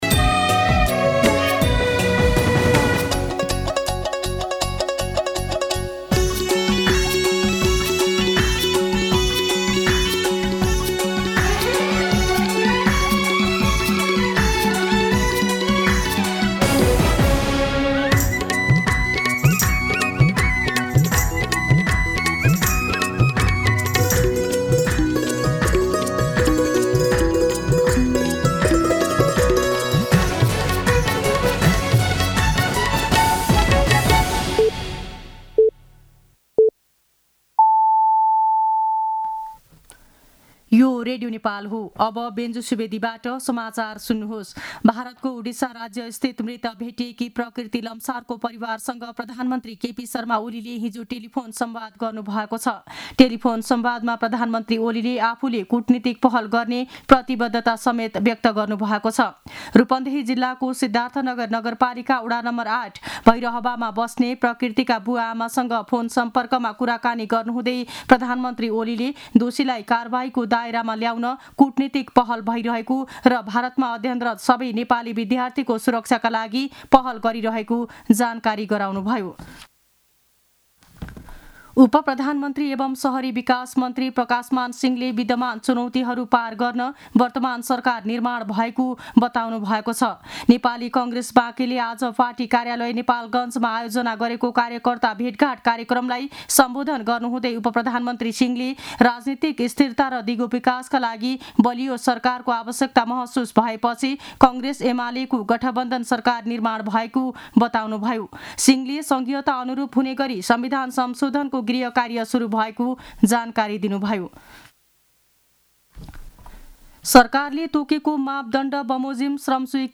दिउँसो १ बजेको नेपाली समाचार : १४ फागुन , २०८१